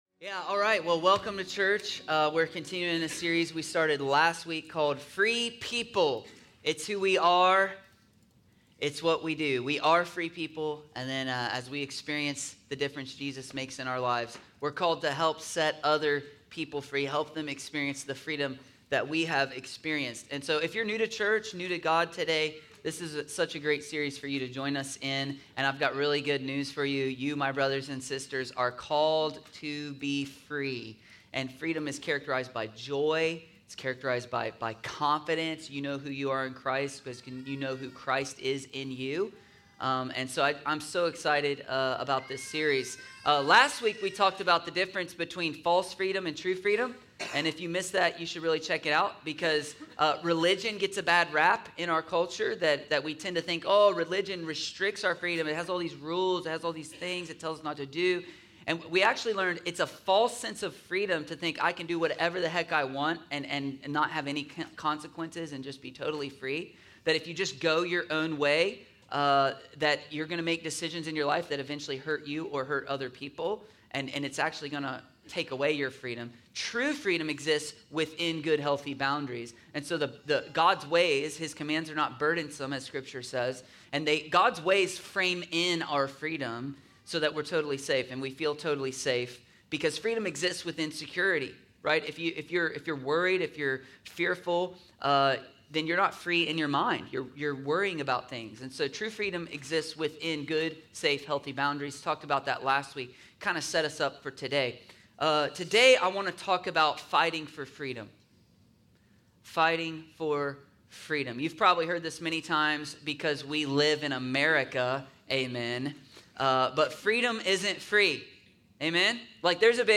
A message from the series “FREE People.”…